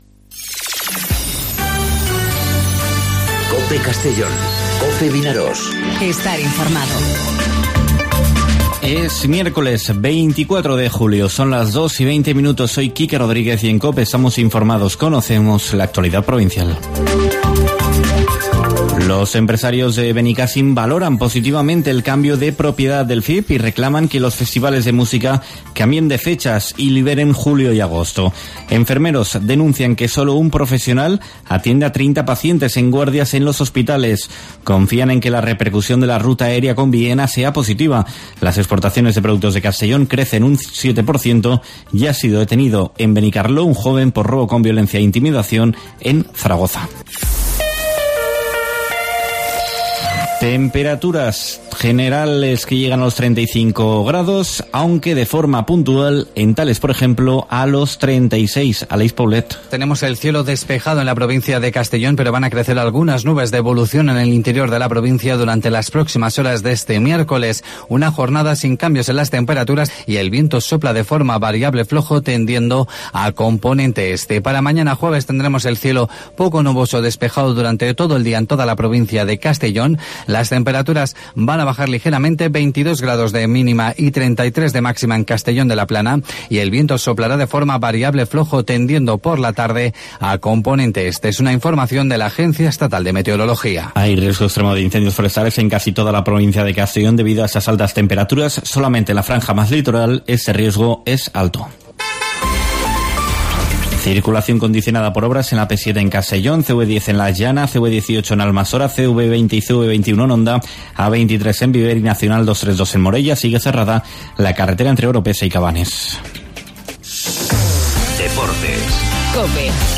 Informativo 'Mediodía COPE' en Castellón (24/07/2019)